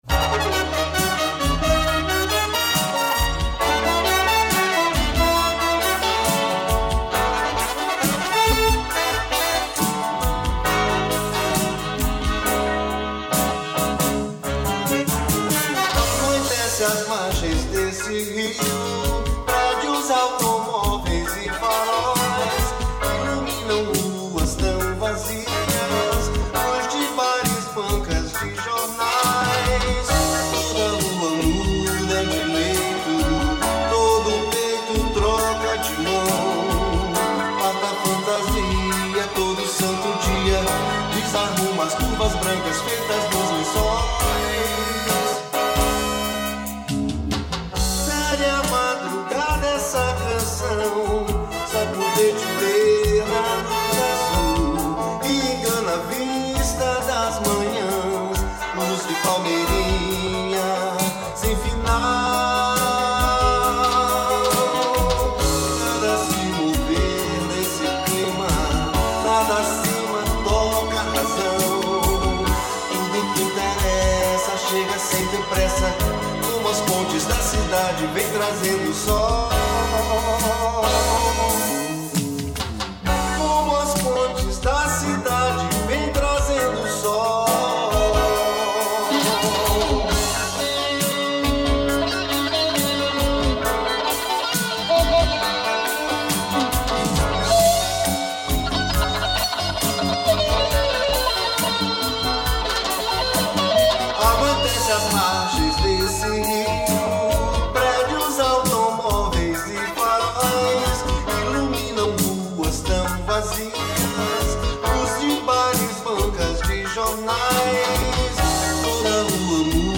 697   04:59:00   Faixa:     Mpb